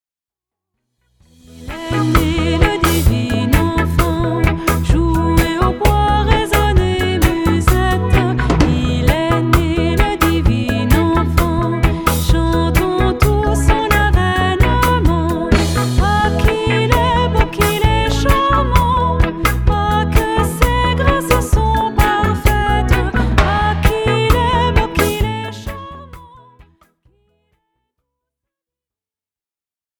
Les grands classiques de Noël
voix cristalline